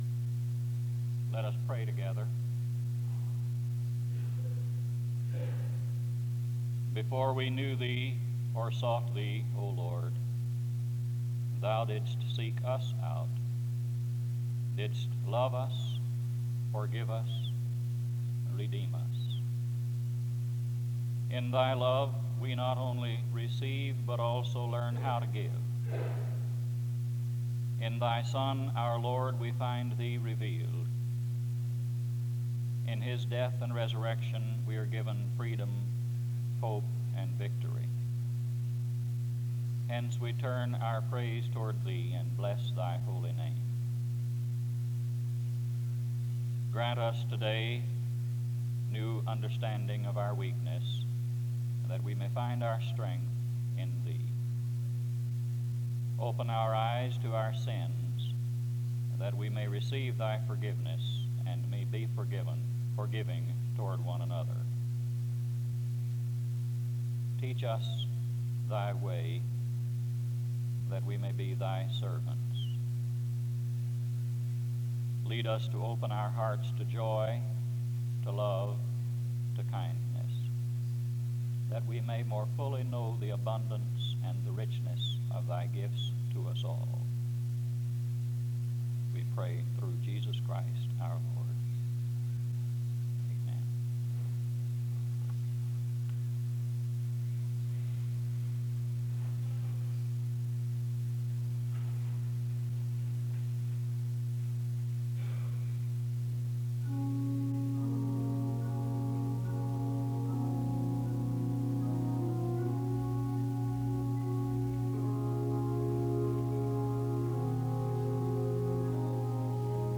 The service begins with a prayer (0:00-1:39). The service continues with a period of singing (1:40-5:12).